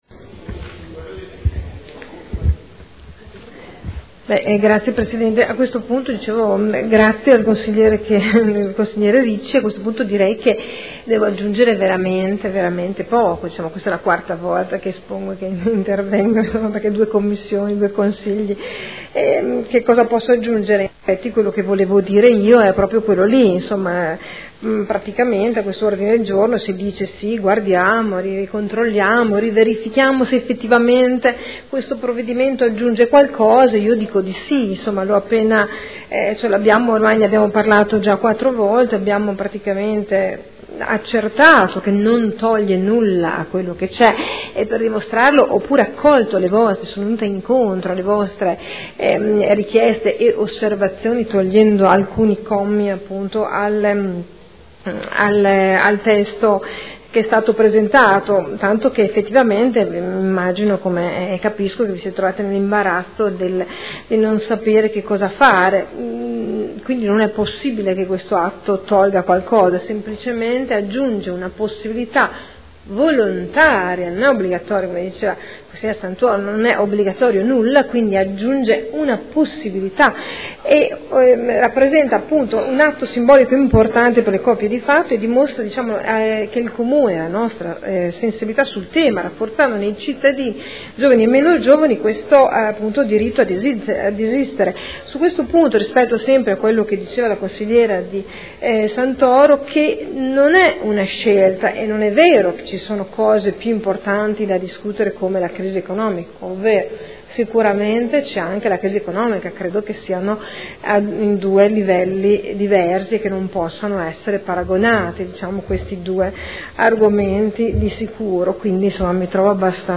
Seduta del 7 aprile.
Dibattito